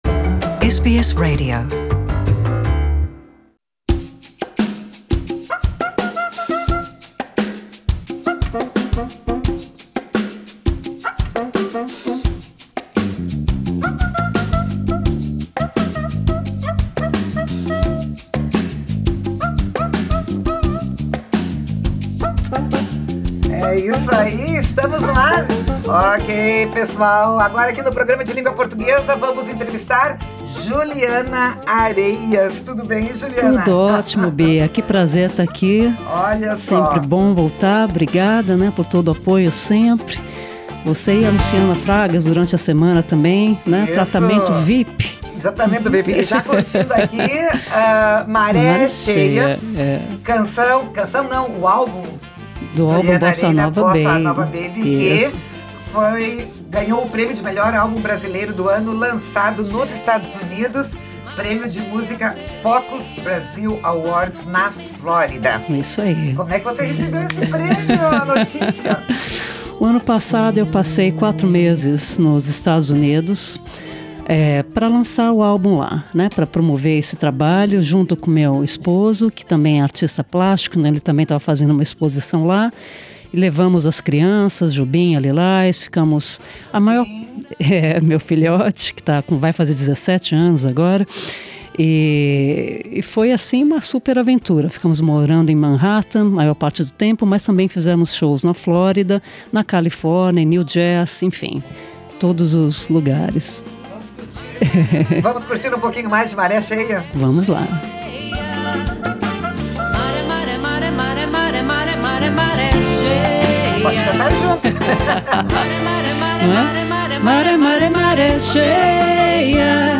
SBS em Português